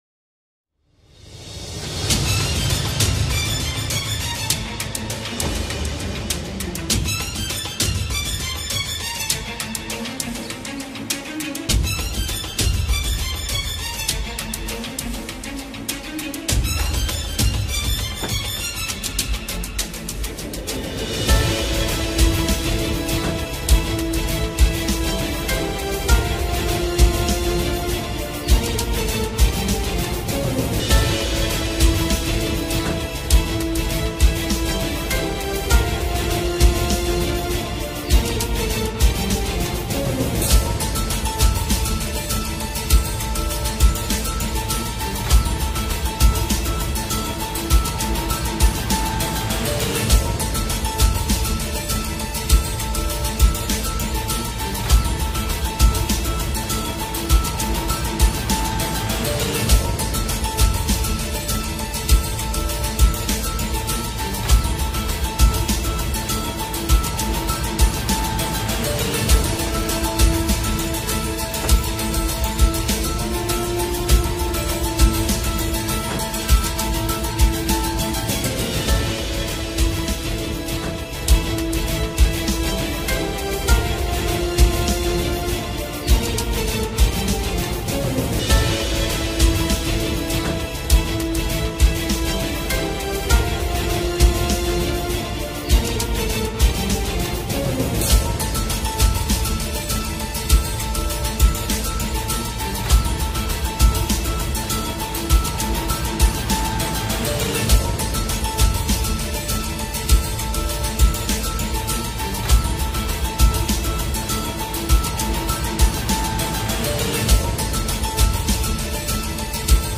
tema dizi müziği, heyecan gerilim aksiyon fon müziği.